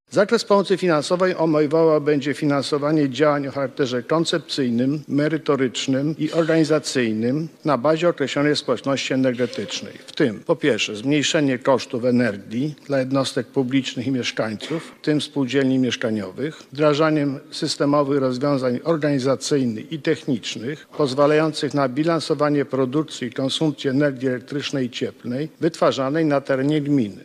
-mówi marszałek województwa mazowieckiego Adam Struzik.